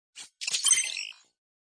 TextAppear.wav